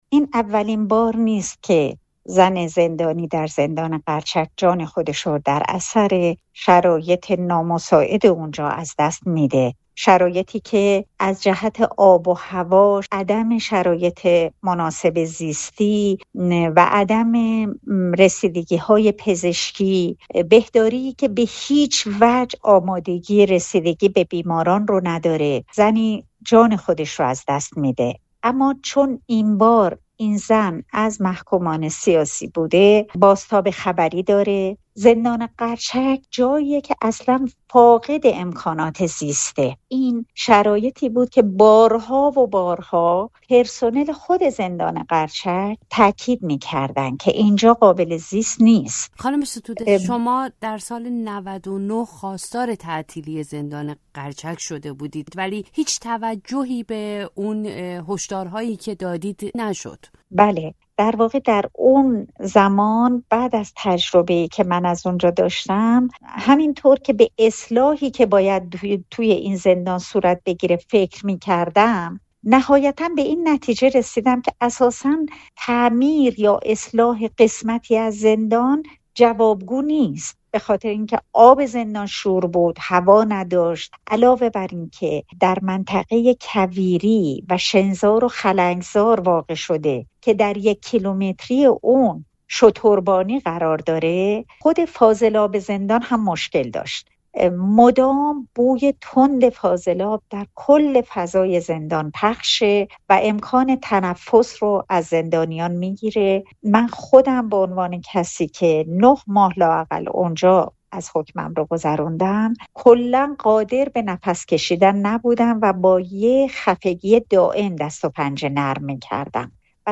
در زندان قرچک چه می‌گذرد؟ گفت‌وگو با نسرین ستوده